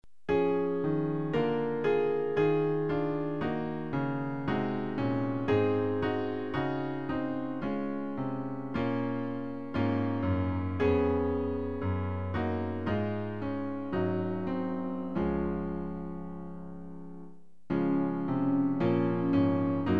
Klavier-Playback zur Begleitung der Gemeinde
MP3 Download (ohne Gesang)
Themenbereich: Jesuslieder